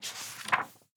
Book Page (1).wav